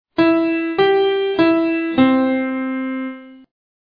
Major chords hidden in the C minor scale